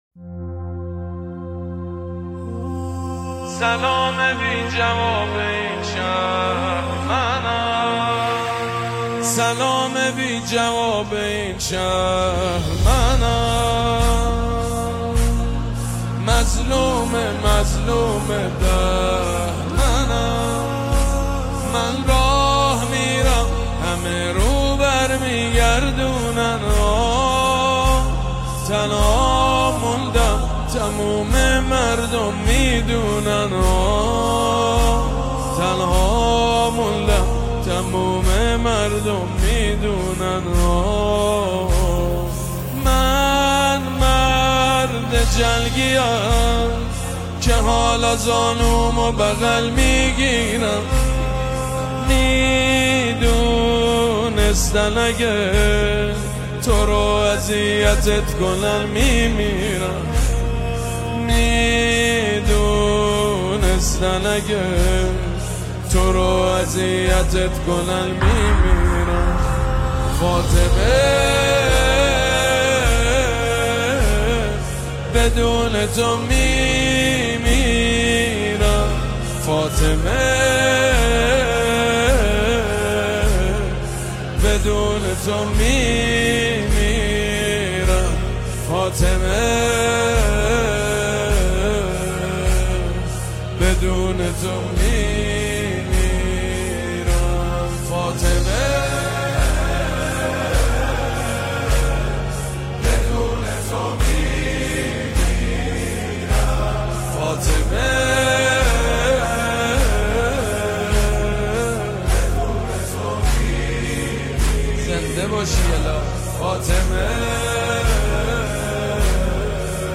نماهنگ مذهبی مداحی مذهبی